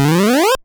その他の効果音
溜めるＡ４段